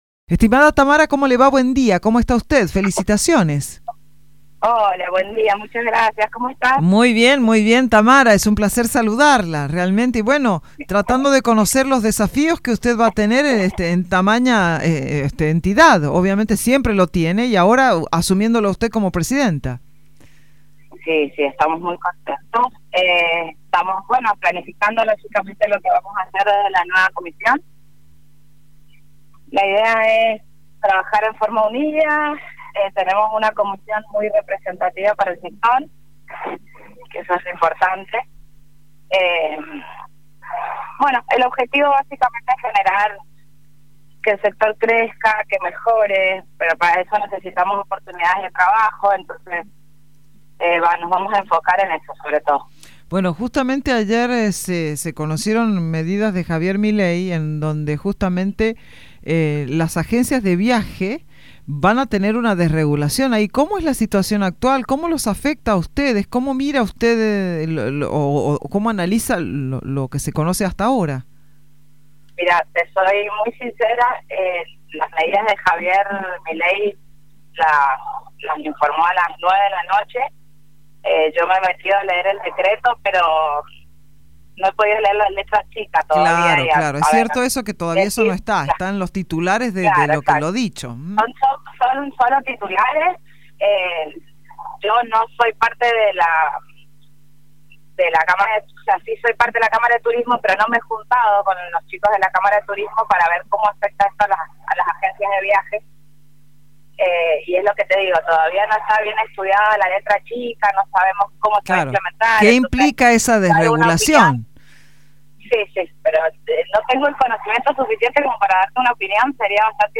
En una entrevista para Radio Sarmiento, la representante dio cuenta de cuáles son los principales desafíos que enfrentará como cabeza del sector.